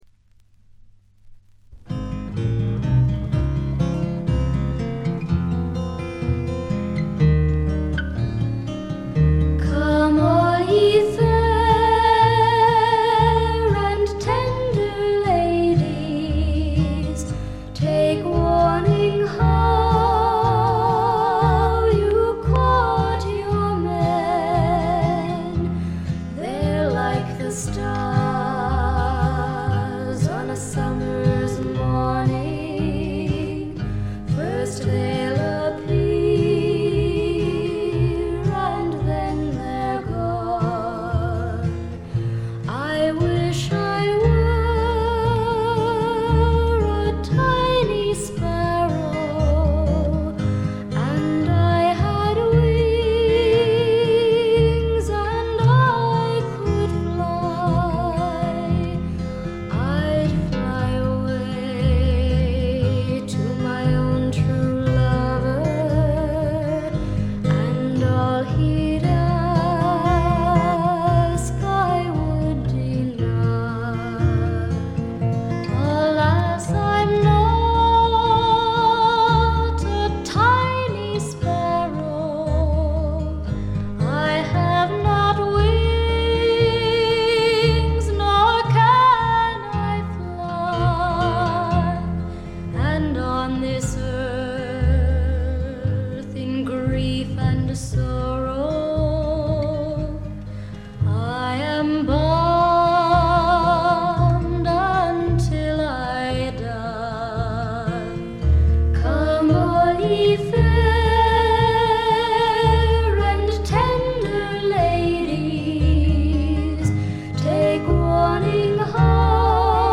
わずかなチリプチ程度。
カナダの女優／歌姫による美しいフォーク作品です。
この時点でまだ20歳かそこらで、少女らしさを残したかわいらしい歌唱がとてもよいです。
試聴曲は現品からの取り込み音源です。